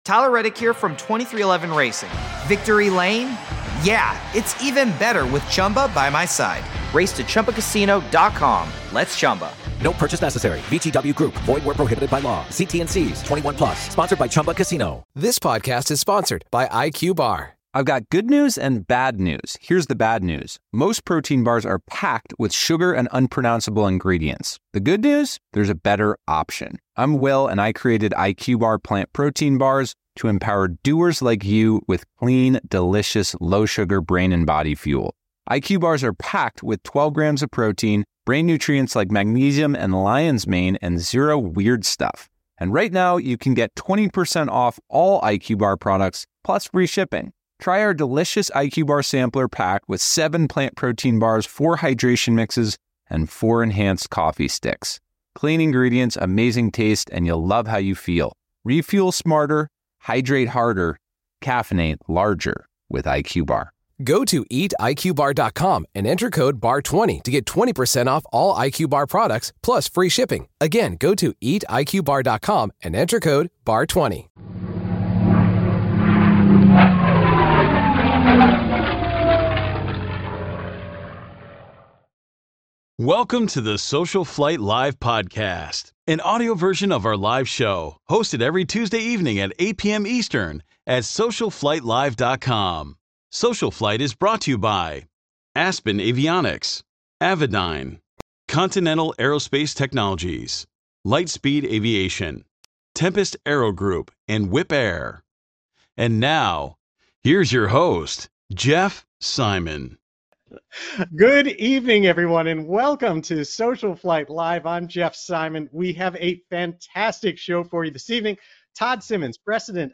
“SocialFlight Live!” is a live broadcast dedicated to supporting General Aviation pilots and enthusiasts during these challenging times.